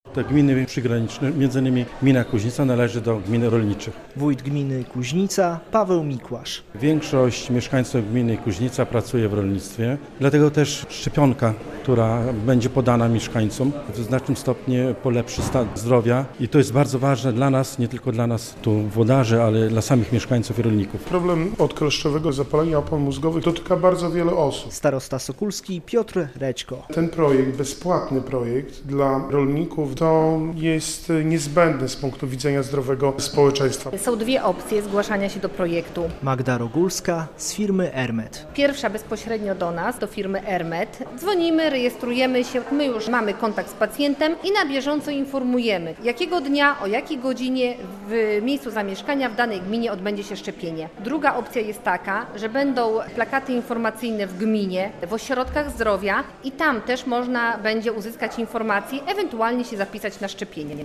Bezpłatne szczepienia dla rolników przeciwko kleszczowemu zapaleniu mózgu - relacja